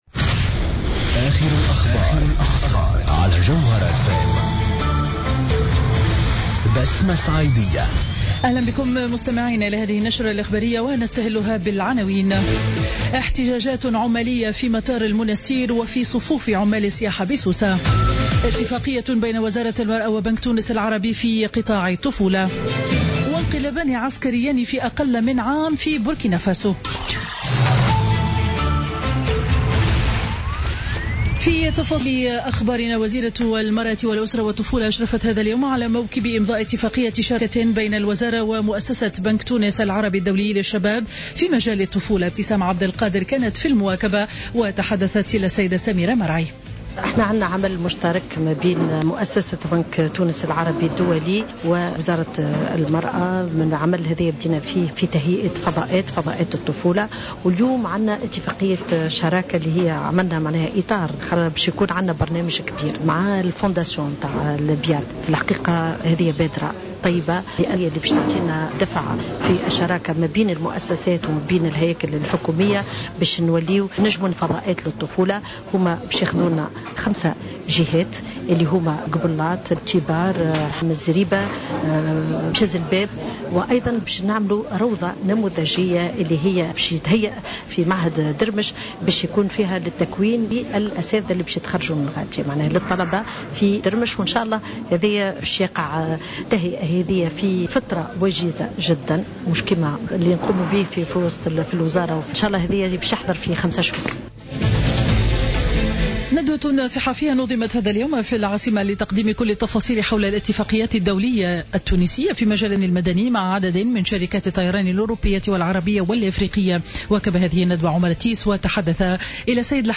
نشرة أخبار منتصف النهار ليوم الجمعة 18 سبتمبر 2015